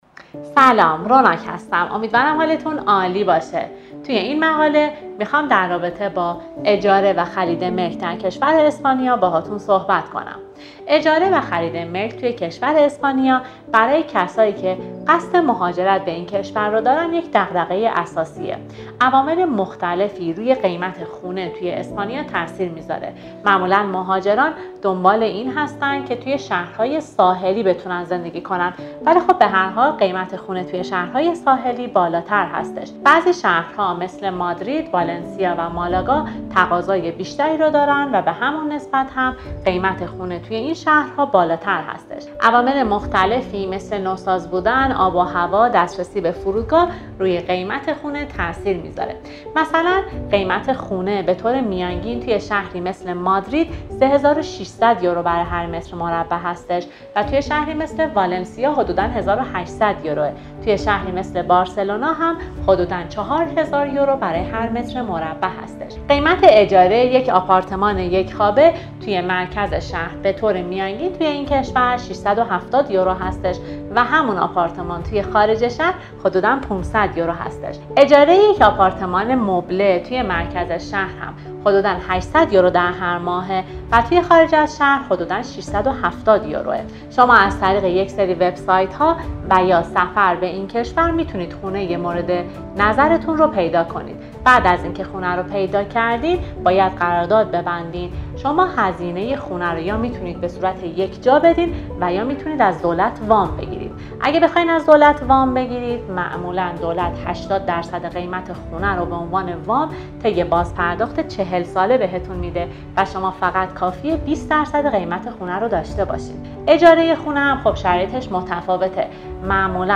پادکست اجاره و خرید ملک در اسپانیا